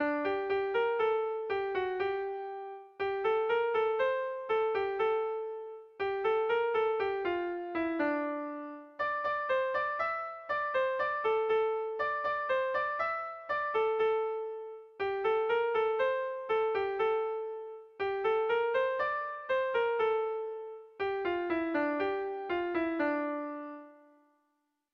Sentimenduzkoa
Bertsolari
Zortzikoa, berdinaren moldekoa, 7 puntuz (hg) / Zazpi puntukoa, berdinaren moldekoa (ip)
ABDEBFG